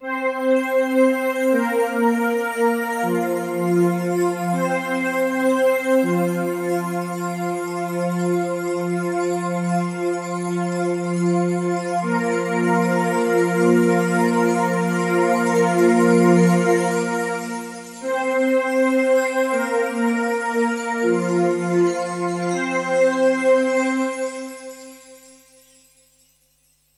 Cortinilla musical del cuento: Ernesto no puede
melodía
Sonidos: Música